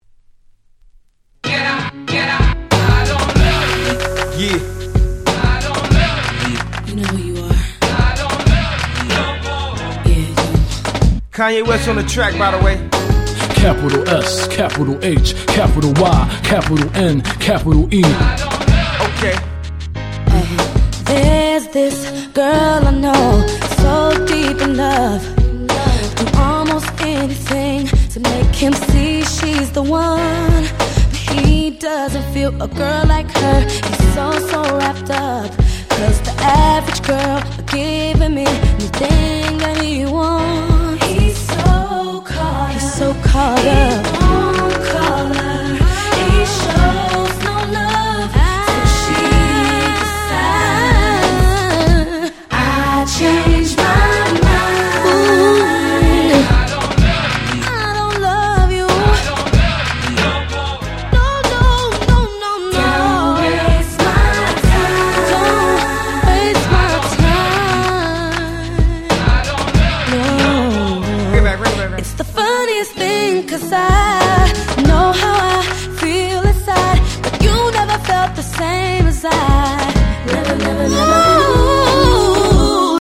03' Very Nice R&B !!